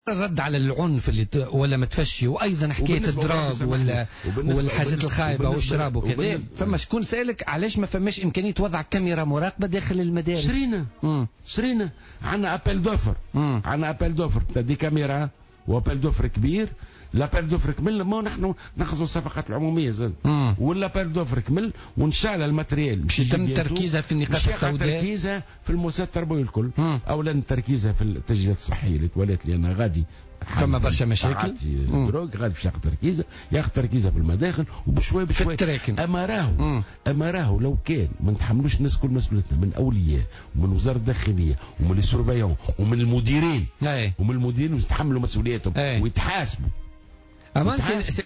وأضاف الوزير في مداخلة له في برنامج "بوليتيكا" اليوم أنه سيتم تركيز كاميرات مراقبة في مداخل دورات المياه و محيط جميع المؤسسات التربوية، مشيرا إلى أن مسؤولية انتشار العنف يتحملها الإطار التربوي والأولياء على حدّ السواء، وفق تعبيره.